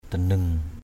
/d̪a-nɯŋ/